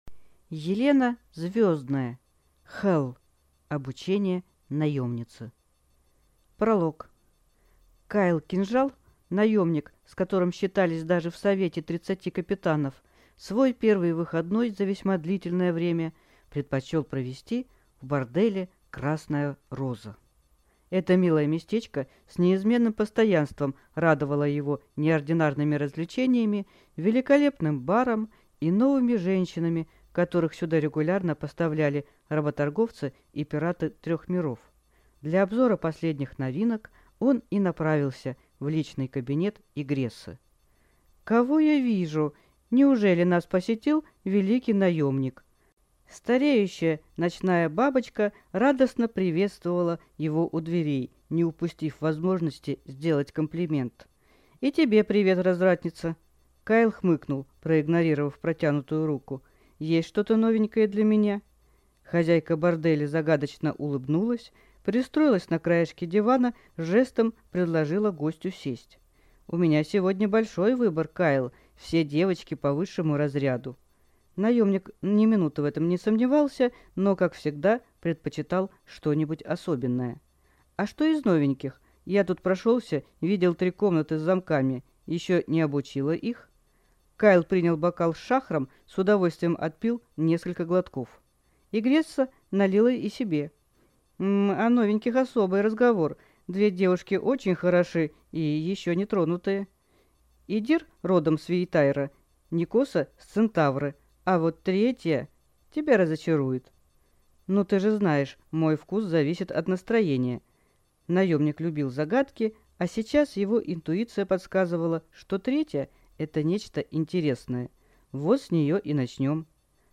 Аудиокнига Хелл.